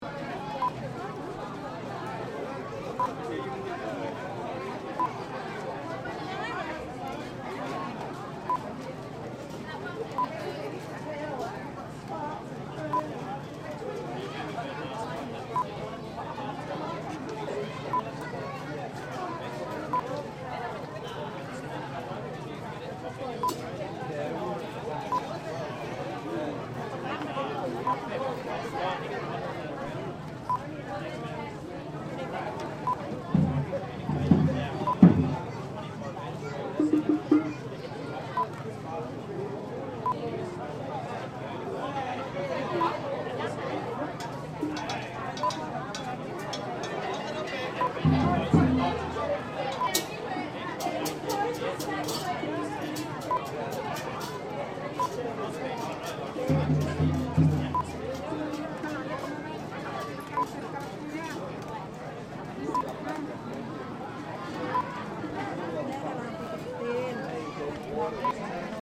Bali Oge Oge Street Parade, Carnival, Festival 3 (sound FX)
Bali Oge oge new year street parade, recorded in Kuta the night before Nyepi. Lots of crowd hubbub, chatter, laughter. Children shouting and playing. Mainly Indonesian voices there are also Australian and English voices. People walking by. children scream. Some drums rolls and kadjar hits.
IndonesianStreetParade3_plip.mp3